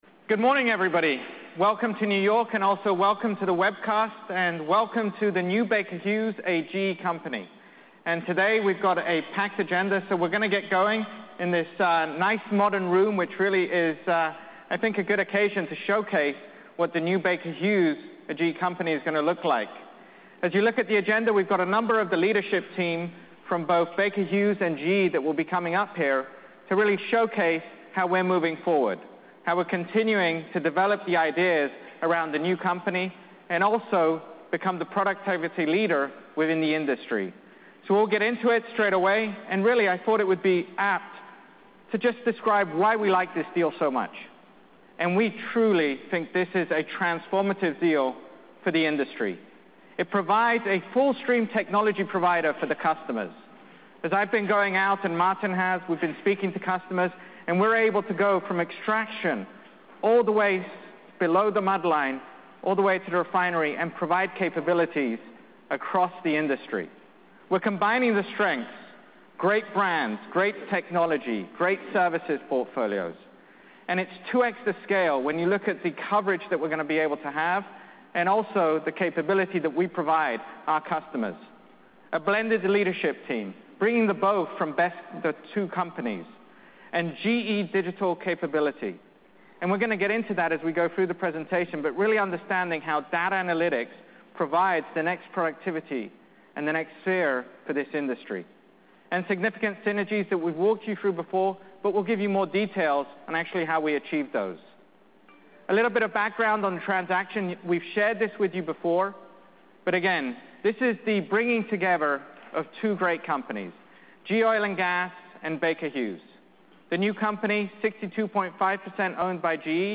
Leaders from Baker Hughes and GE Oil & Gas presented.